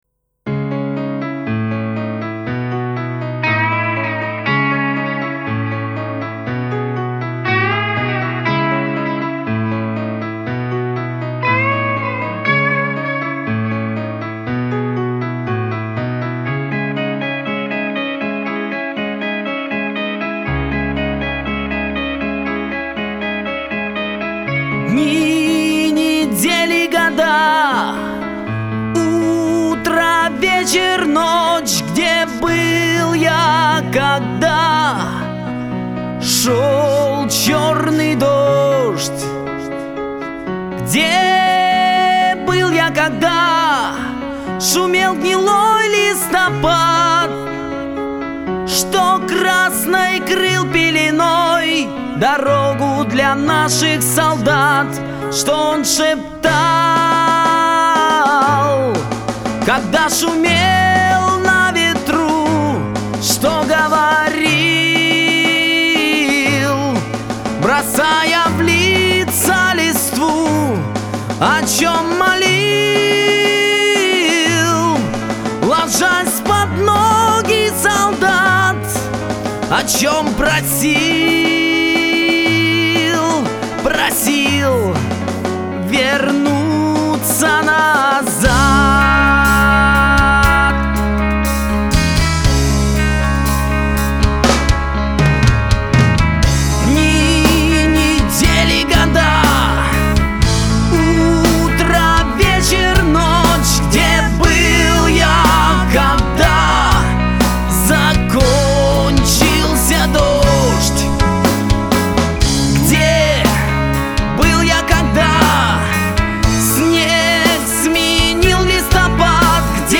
В заключение приведу прискорбную песню об ужасе Войны...